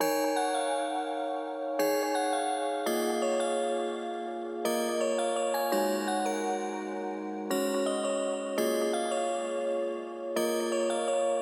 Tag: 168 bpm Trap Loops Bells Loops 1.92 MB wav Key : E Logic Pro